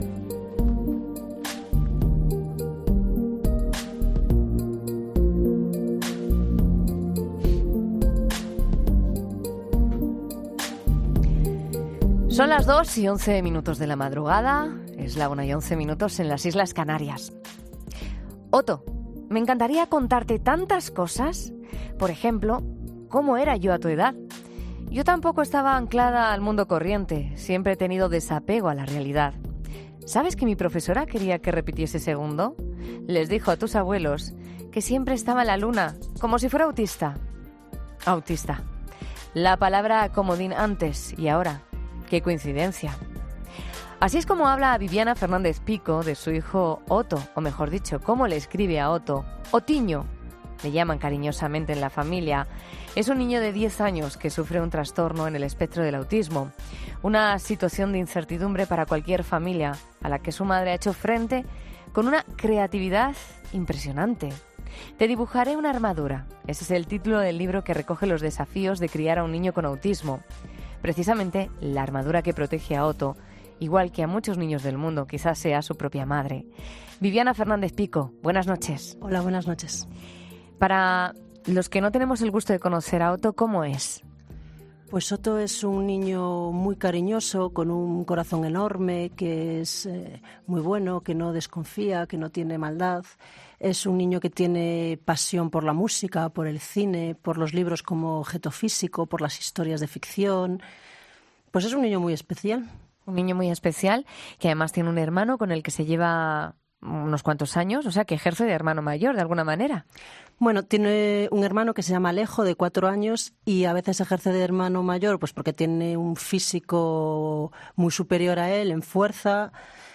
En una entrevista este martes en 'La Noche' de COPE